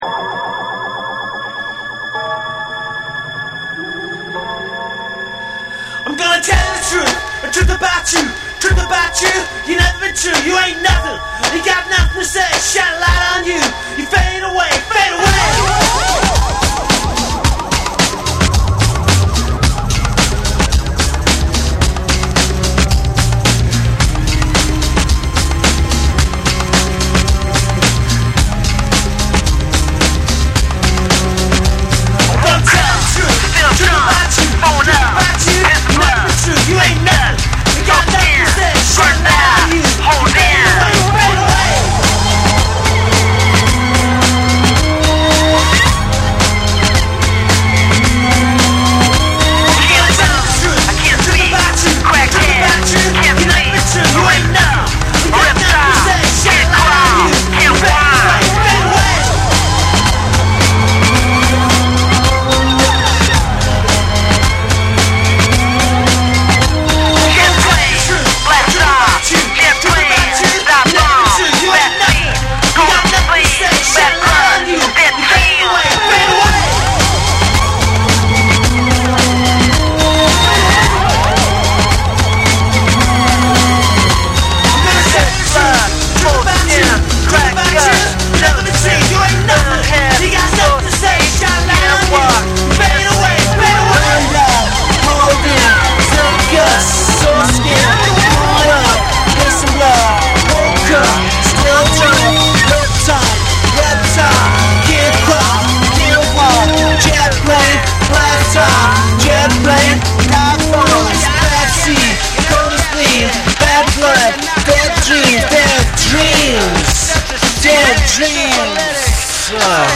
メリハリのある重心低めのブレイクビーツにサイケデリックな演奏が交わる
歪んだベースとノイジーなギター、電子音が交錯する、アグレッシヴでインダストリアルなエレクトロ・ロック
NEW WAVE & ROCK